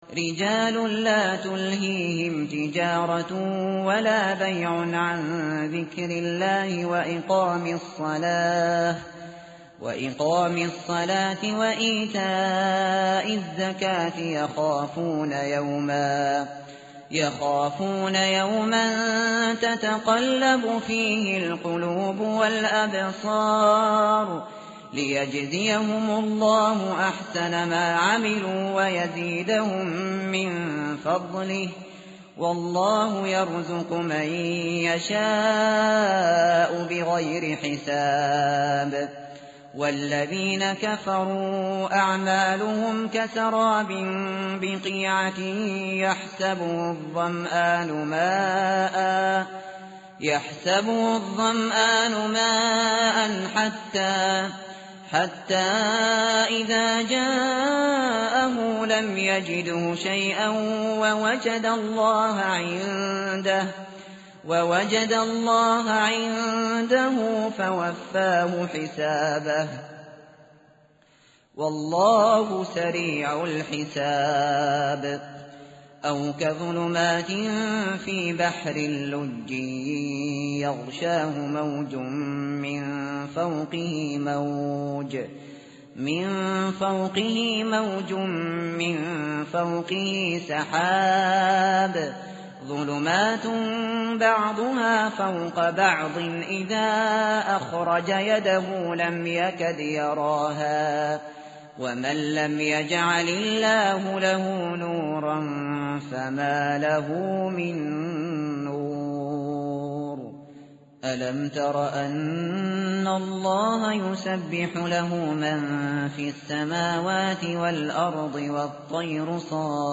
tartil_shateri_page_355.mp3